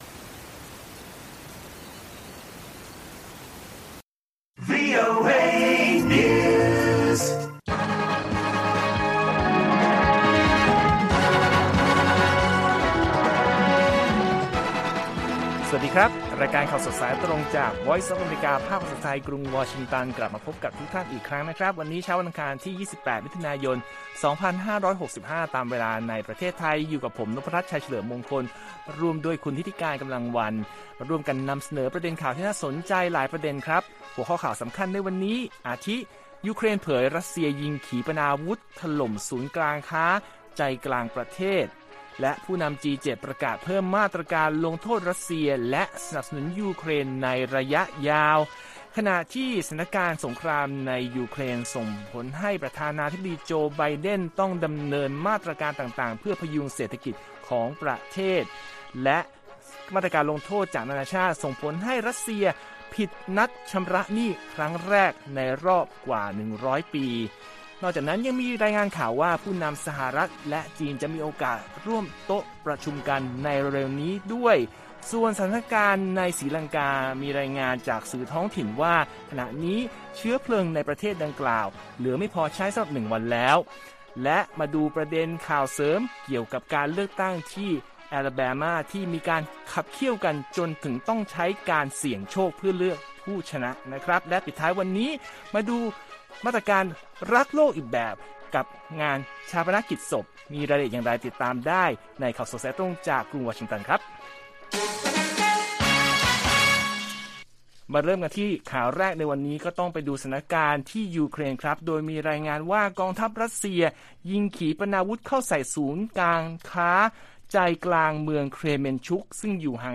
ข่าวสดสายตรงจากวีโอเอ ไทย อังคาร 28 มิ.ย.2565